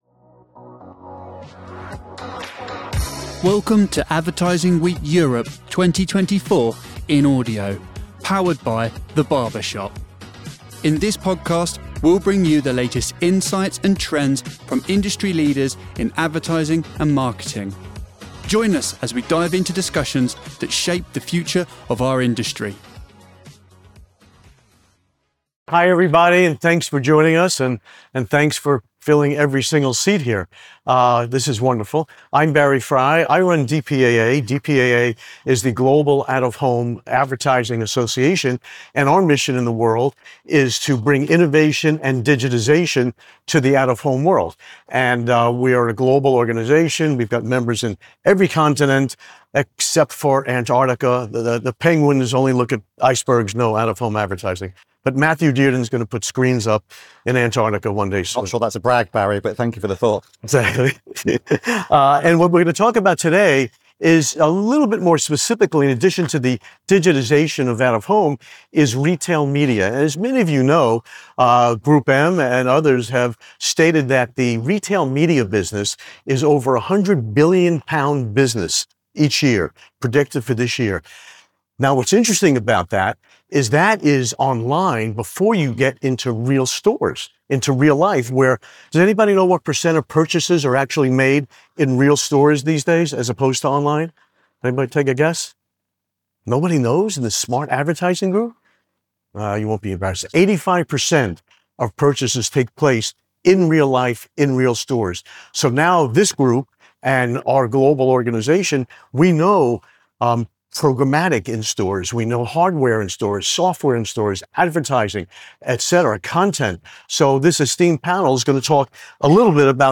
Uncover the expanding influence of retail media and digital out-of-home (DOOH) advertising in the omnichannel retail environment. This session, featuring insights from DPAA, Vistar Media, Alight Media, Assembly, and VIOOH, examines how these mediums are impacting consumer behavior and shaping marketing strategies. The panel discusses innovative approaches to integrating retail media and DOOH into omnichannel campaigns to engage consumers across multiple touchpoints effectively.